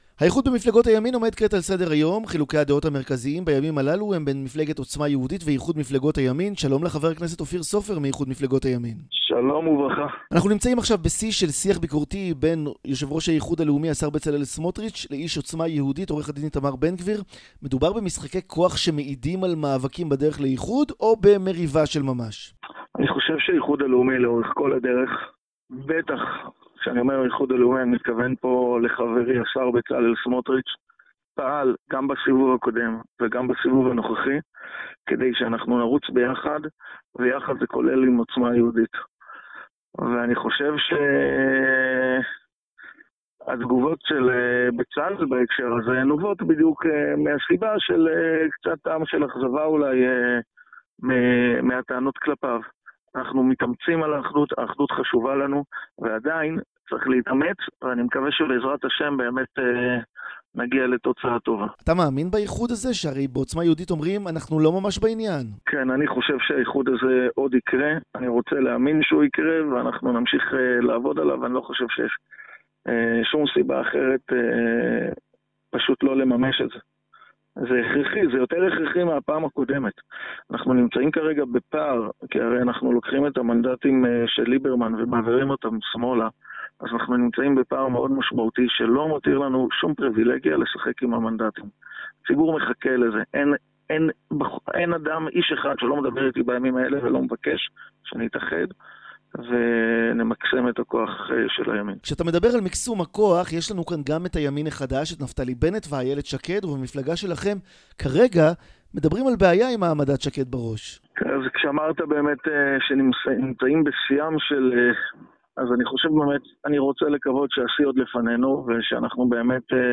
«Я думаю, что «Ихуд Леуми», и, конечно, министр Бецалель Смотрич, действовал как в предыдущем раунде, так и в текущем, чтобы мы могли идти на выборы вместе, включая «Оцма Иегудит». Реакция Бецалеля вызвана разочарованием по поводу претензий к нему. Мы стремимся к единству, это очень важно для нас, и по-прежнему необходимо, поэтому я надеюсь, что в итоге мы достигнем хорошего результата», - в частности, сказал он в интервью корреспонденту 7 канала.